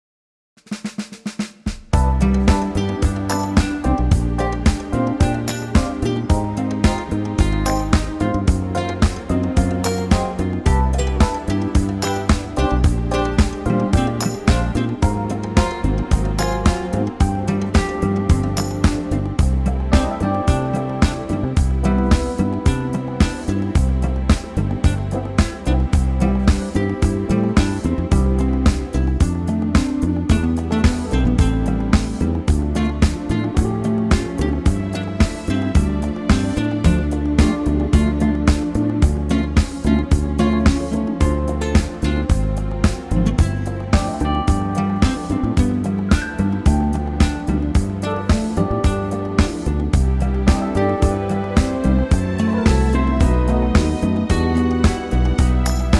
ALL BRASS REMOVED!
key - C - vocal range - B to D
Here's a Rhumba tempo but with an 80's vibe going on!